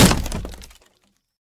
woodenbreak.ogg